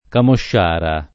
[ kamošš # ra ]